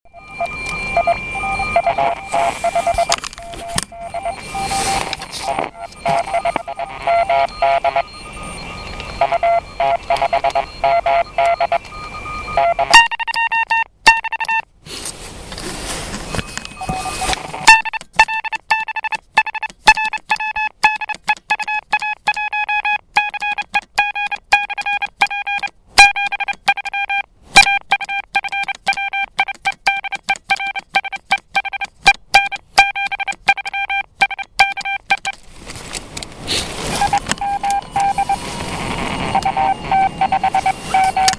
Выходил поработать QRP/p в поля- леса. Цель была проверить приём на приёмнике прямого преобразования в реальном эфире.
И аудио, приём на ППП, вообще-то это ТПП, но передатчик, пока, и не востребован...